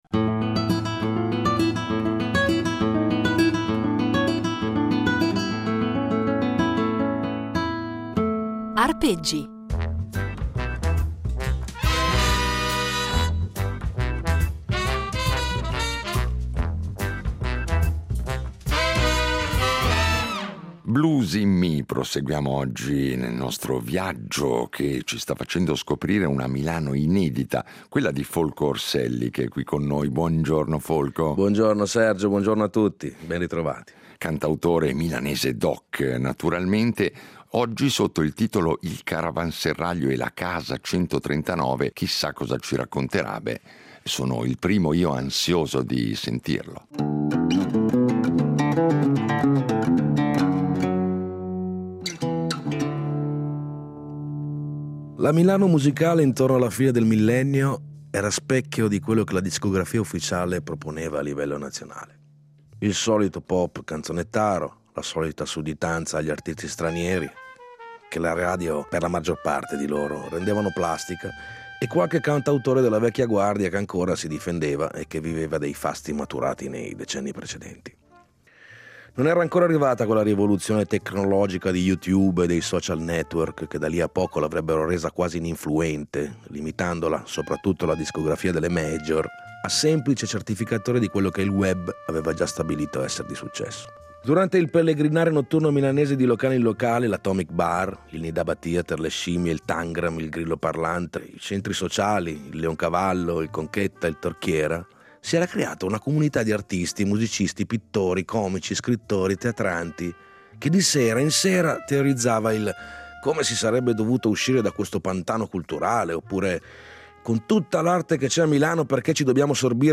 Un itinerario impreziosito, in ogni puntata, da un brano eseguito solo per noi, ai nostri microfoni.